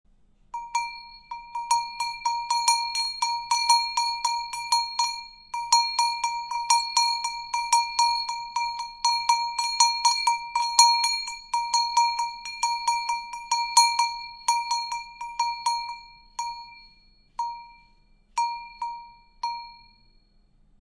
cowbell-6741.mp3